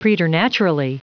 Prononciation du mot preternaturally en anglais (fichier audio)
Prononciation du mot : preternaturally